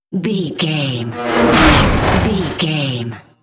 Sci fi whoosh electronic flashback
Sound Effects
Atonal
futuristic
tension
whoosh